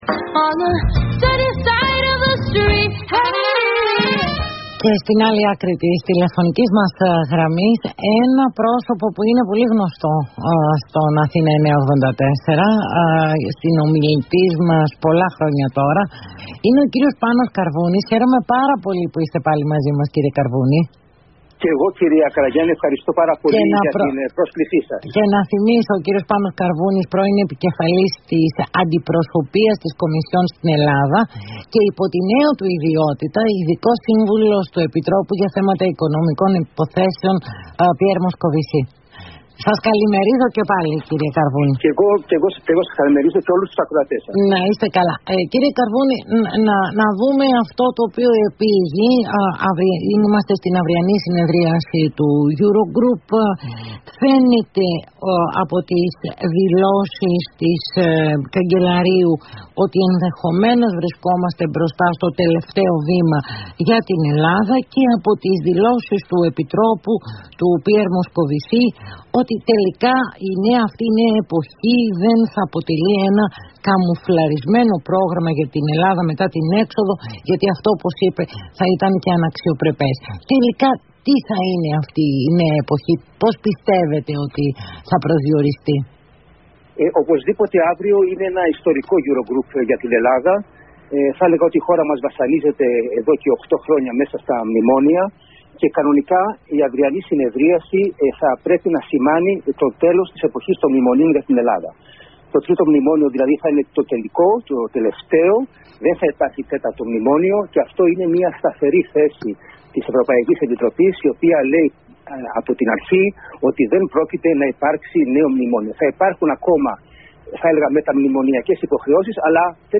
Ο ειδικός σύμβουλος του Επιτρόπου για θέματα Οικονομικών Υποθέσεων Πιέρ Μοσκοβισί και πρώην επικεφαλής της αντιπροσωπείας της Κομισιόν στη χώρα μας, Πάνος Καρβούνης, μίλησε στον Αθήνα 9.84